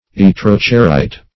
Search Result for " yttro-cerite" : The Collaborative International Dictionary of English v.0.48: Yttro-cerite \Yt`tro-ce"rite\, n. (Min.)